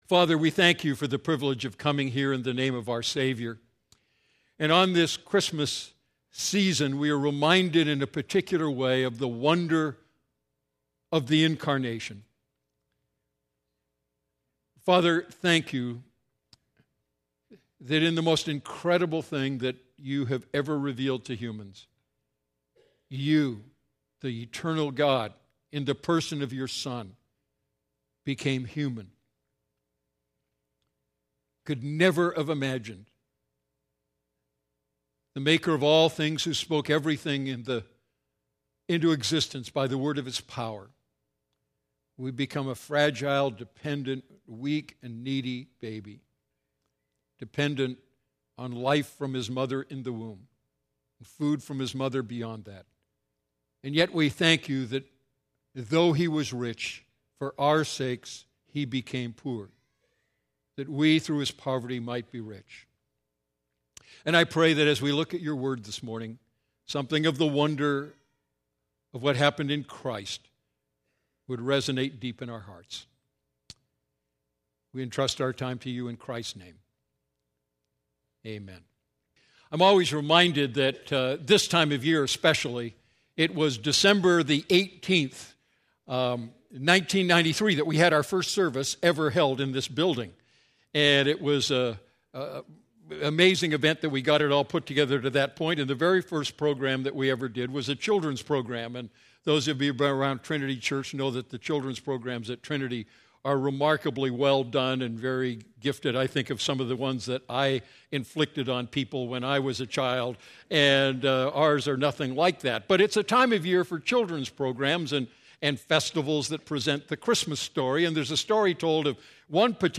A message from the series "The Wonder of Christmas."